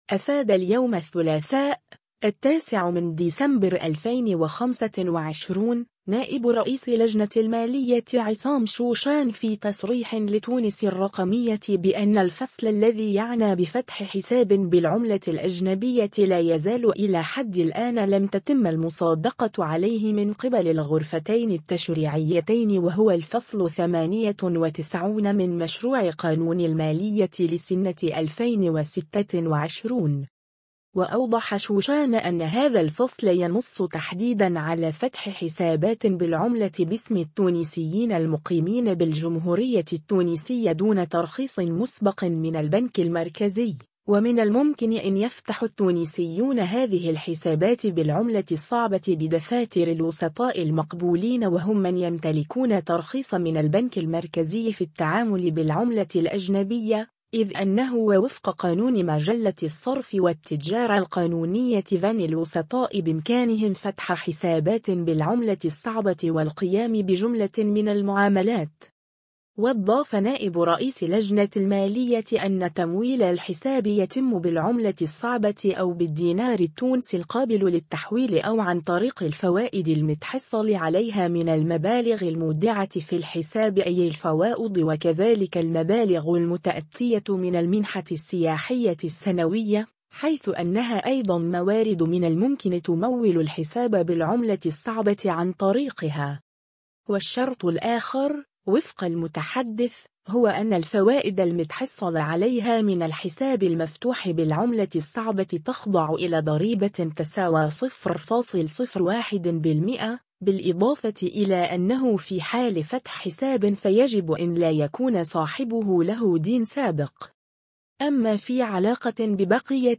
أفاد اليوم الثّلاثاء، 09 ديسمبر 2025، نائب رئيس لجنة المالية عصام شوشان في تصريح لتونس الرّقمية بأنّ الفصل الذّي يعنى بفتح حساب بالعملة الأجنبيّة لا يزال إلى حدّ الآن لم تتمّ المصادقة عليه من قبل الغرفتين التّشريعيّتين و هو الفصل 98 من مشروع قانون المالية لسنة 2026.